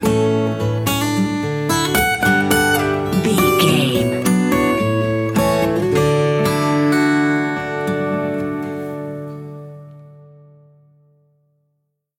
Ionian/Major
D
acoustic guitar